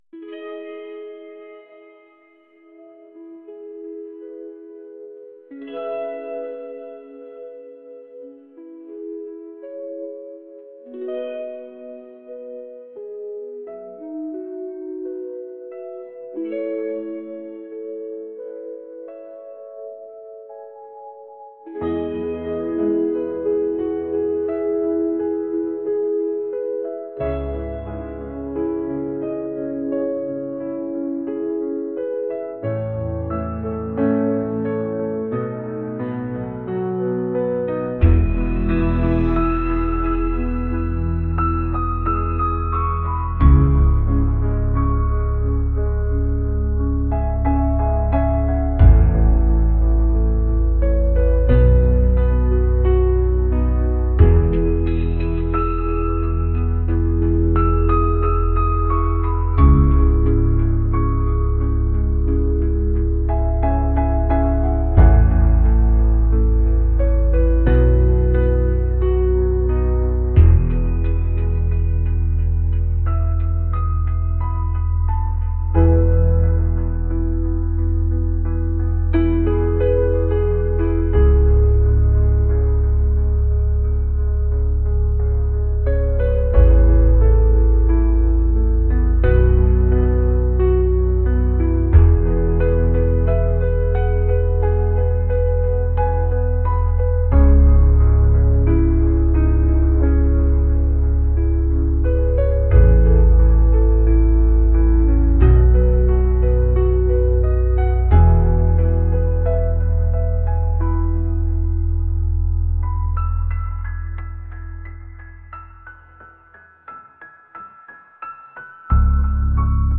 ambient | pop | cinematic